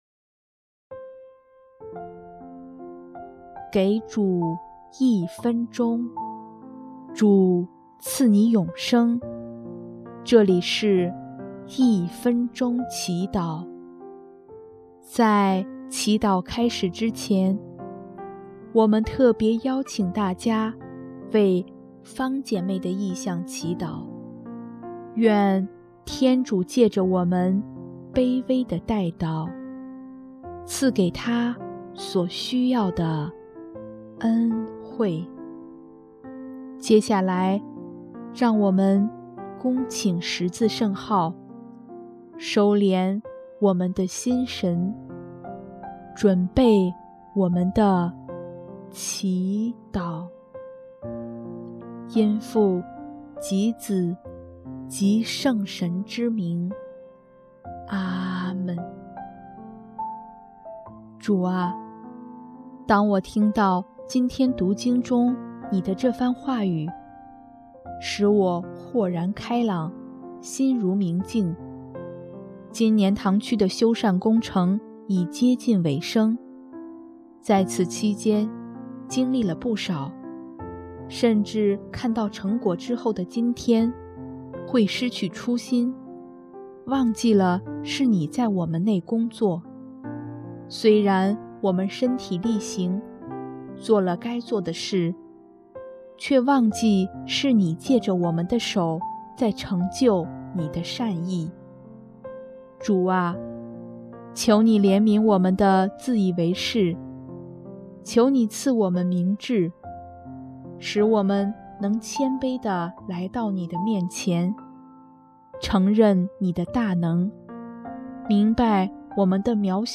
【一分钟祈祷】|11月6日 主，求你教导我们